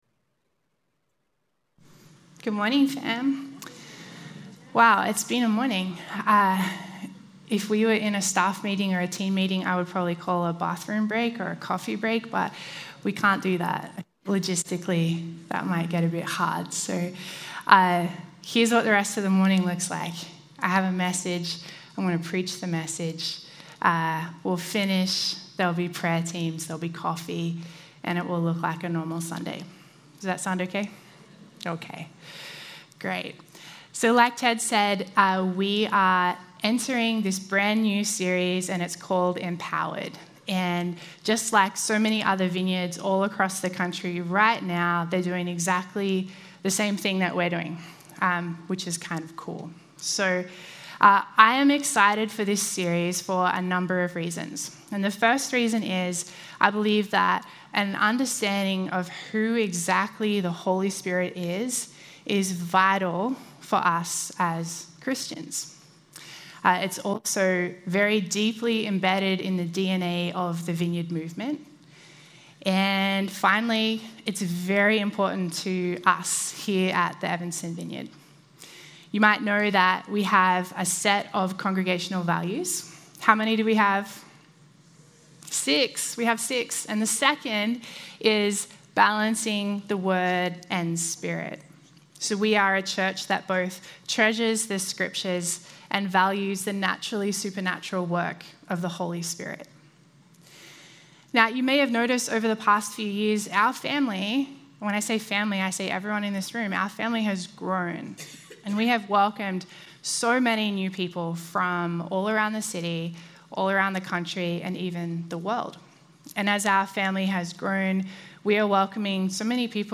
4-16-23-Sermon.mp3